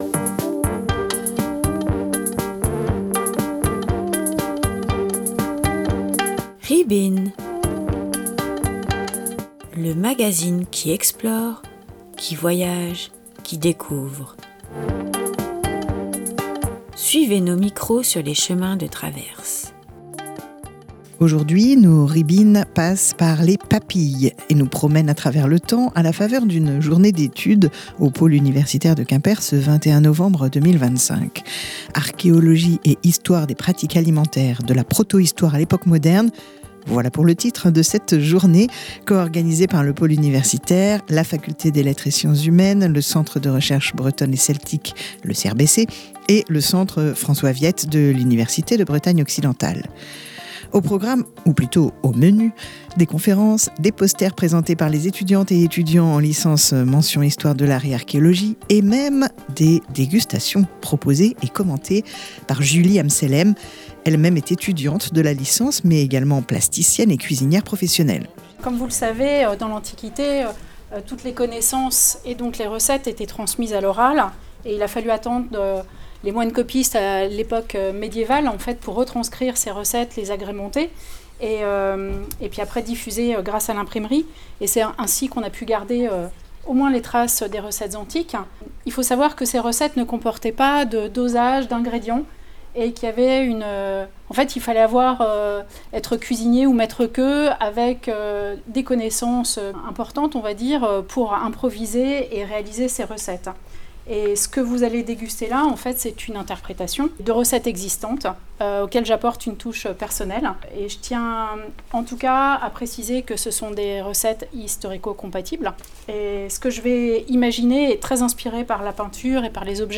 Pour éviter l'indigestion cependant, notre reportage s'arrête à l'Antiquité et c'est déjà copieux !